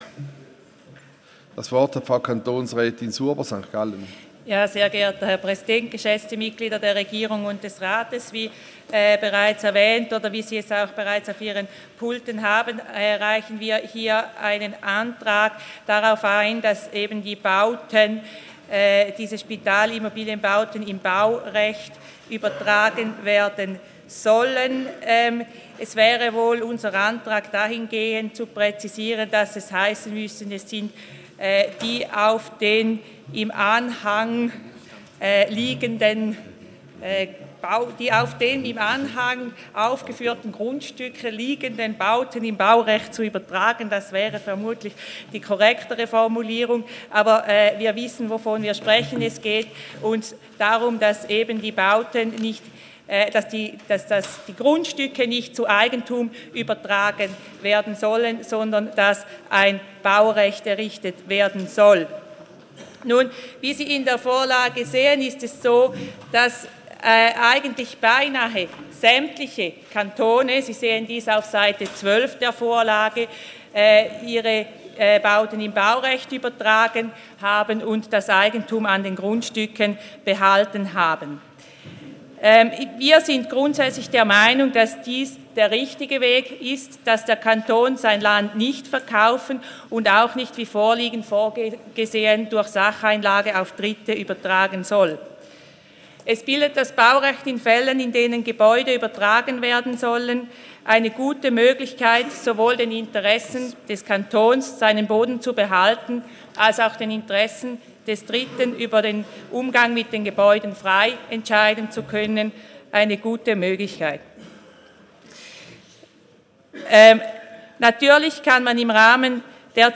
1.3.2016Wortmeldung
Session des Kantonsrates vom 29. Februar bis 2. März 2016, ausserordentliche Session vom 3. März 2016